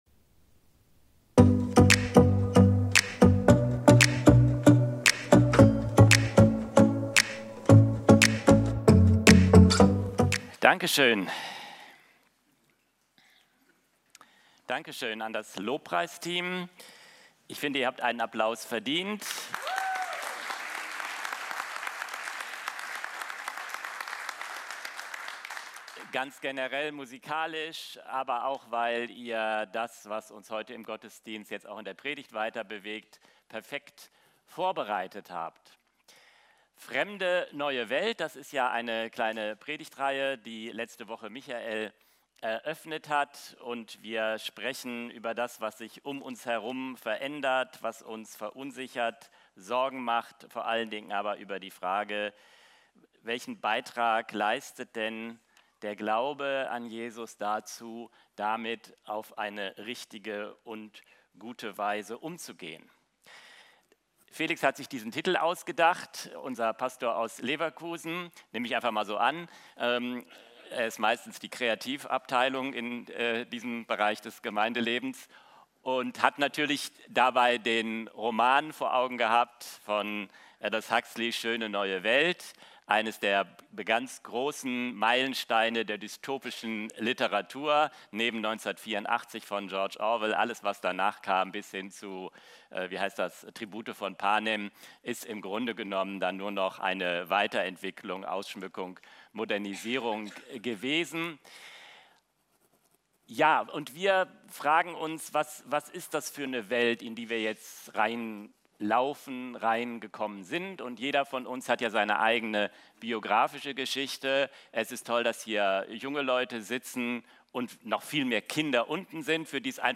Video und MP3 Predigten
Kategorie: Sonntaggottesdienst Predigtserie: Fremde neue Welt